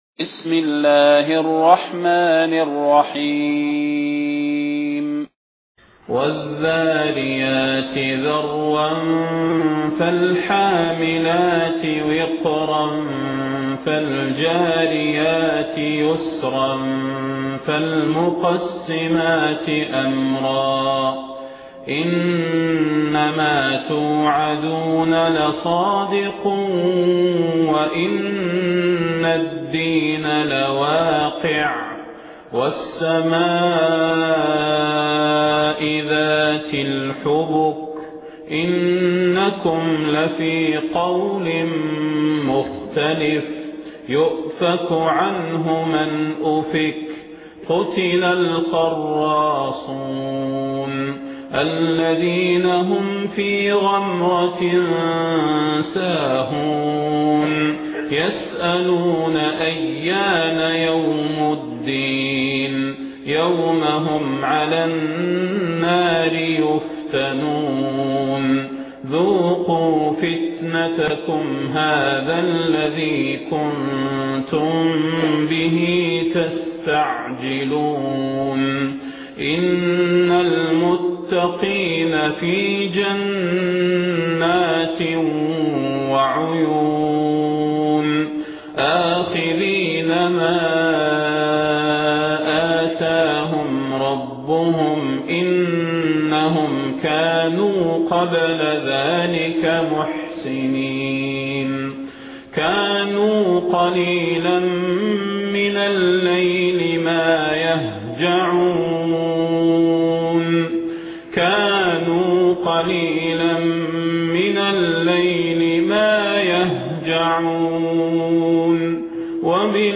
المكان: المسجد النبوي الشيخ: فضيلة الشيخ د. صلاح بن محمد البدير فضيلة الشيخ د. صلاح بن محمد البدير الذاريات